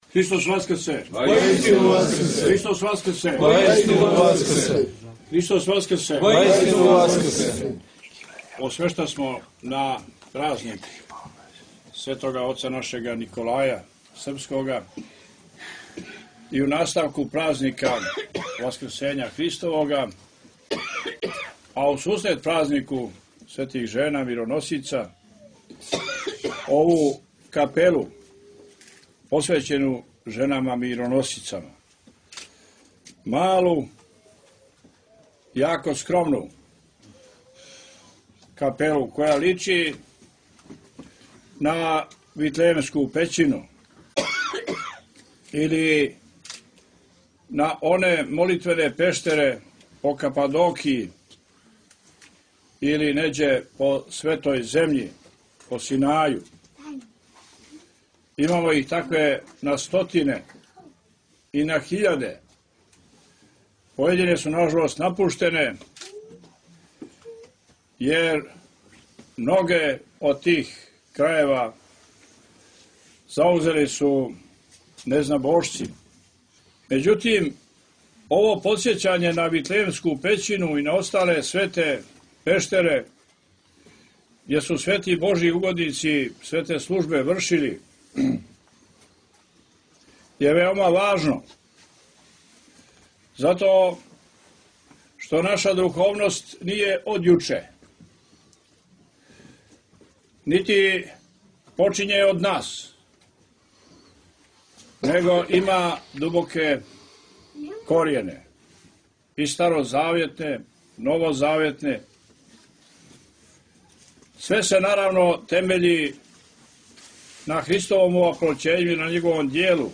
Njegovo visokopreosveštenstvo Mitropolit crnogorsko-primorski g. Joanikije služio je danas, 3. maja 2022. godine, Svetu službu Božiju u manastiru Svetih mironosica […]